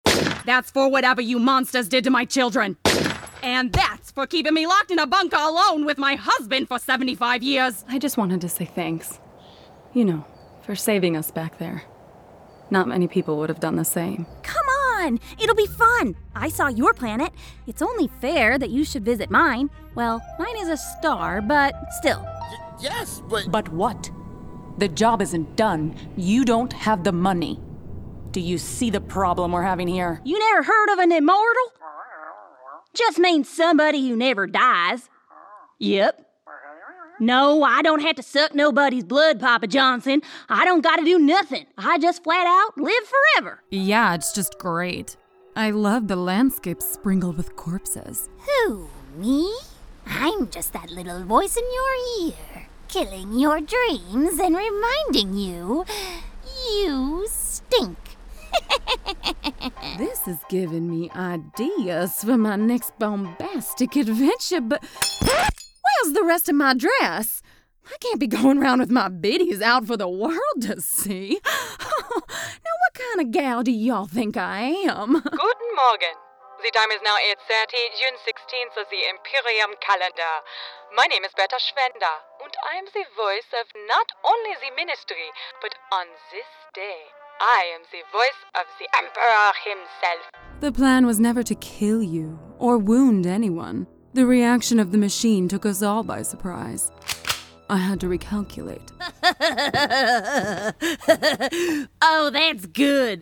Animation
My voice has been characterized as clear, fresh and pleasant.
When it comes to character voices, don’t put it past me to get gritty, goofy, sinister or seductive; whatever it takes to bring a character to life!